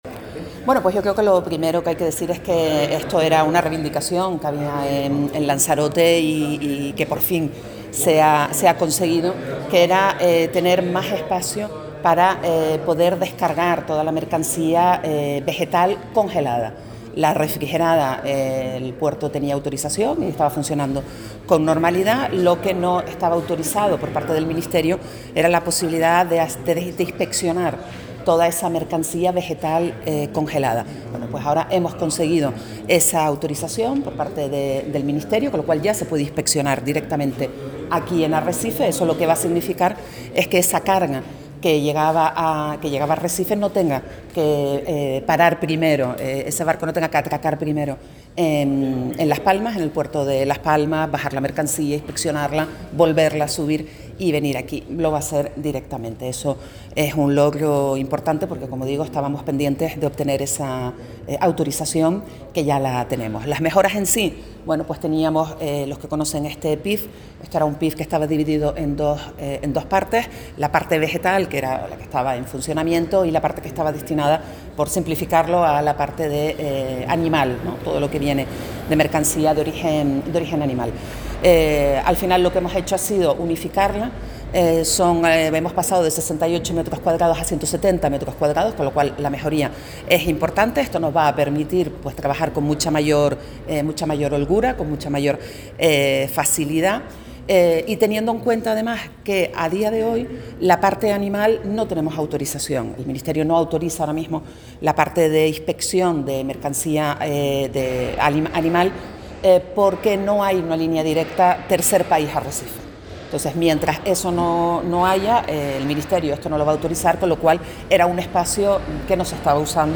DECLARACIONES DE LA PRESIDENTA DE LA AUTORIDAD PORTUARIA DE LAS PALMAS